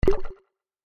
دانلود آهنگ تانک 7 از افکت صوتی حمل و نقل
دانلود صدای تانک 7 از ساعد نیوز با لینک مستقیم و کیفیت بالا
جلوه های صوتی